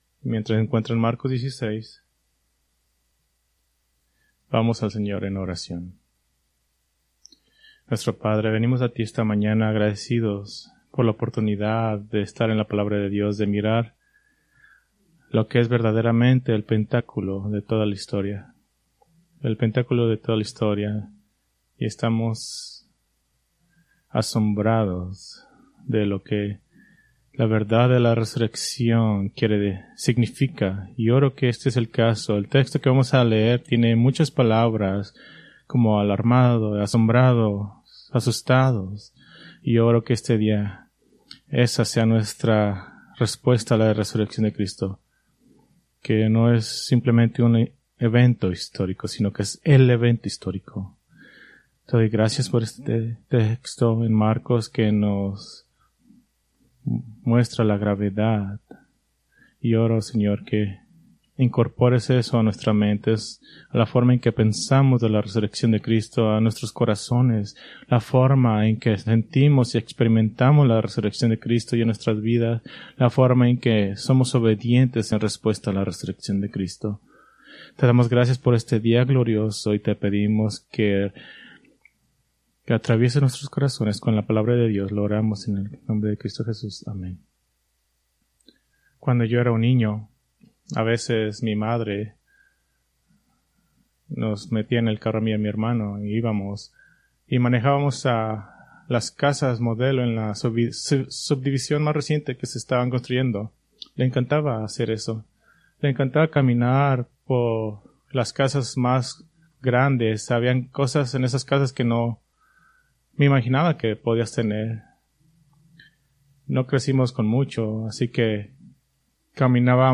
Preached April 20, 2025 from Marcos 16:1-8